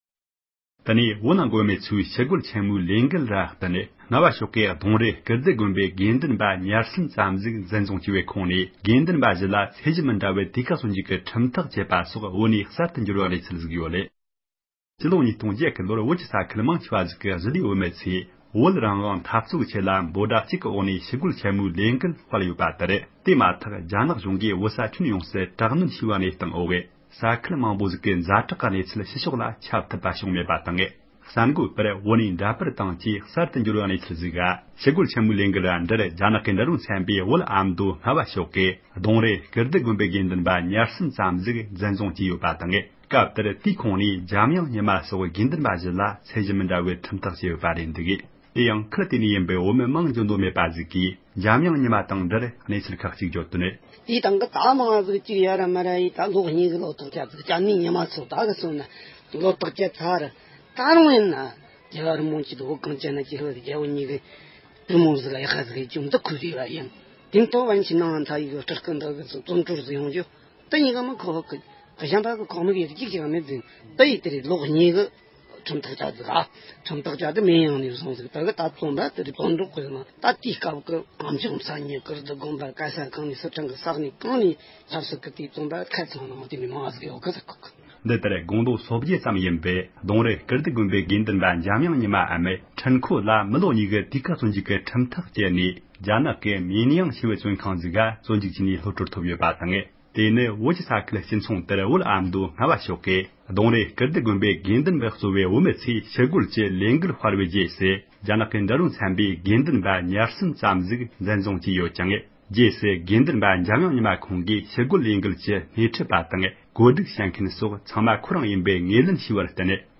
སྒྲ་ལྡན་གསར་འགྱུར།
བོད་ནས་བོད་མི་ཞིག་གིས་